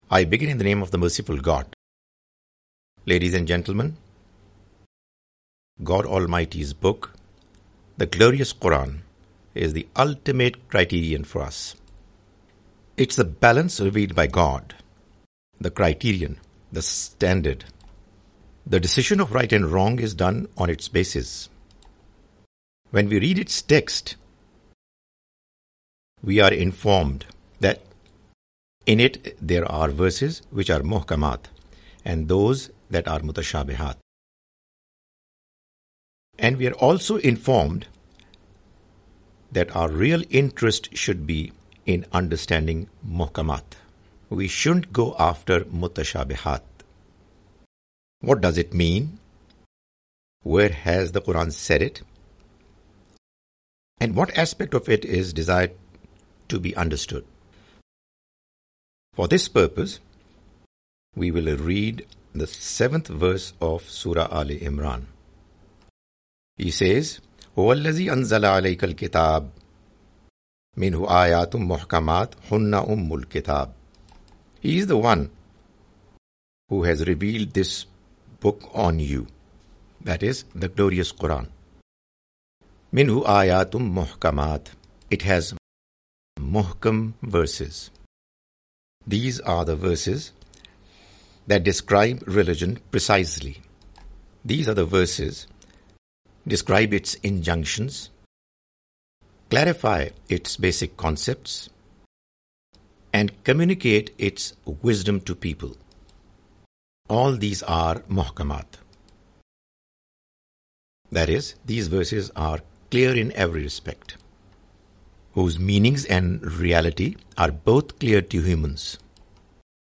The Message of Qur'an (With English Voice Over) Part-7
The Message of the Quran is a lecture series comprising Urdu lectures of Mr Javed Ahmad Ghamidi.